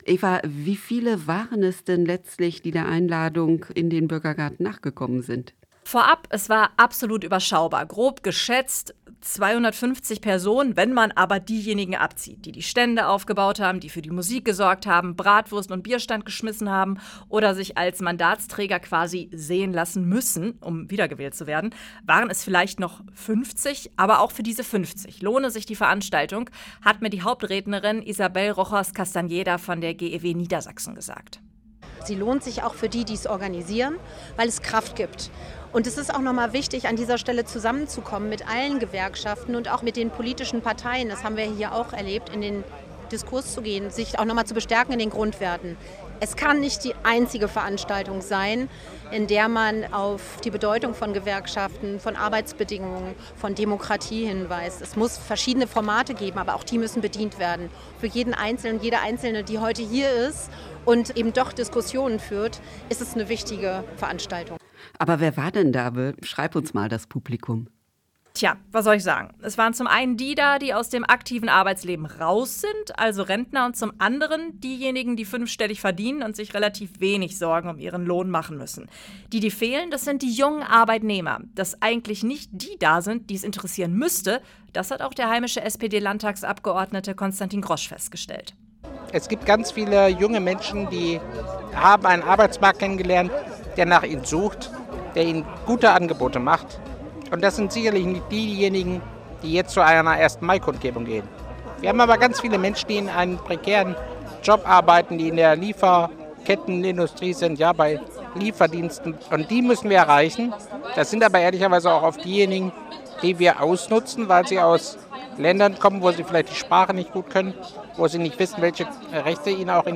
Hameln: DGB-Kundgebung zum „Tag der Arbeit“
hameln-dgb-kundgebung-zum-tag-der-arbeit.mp3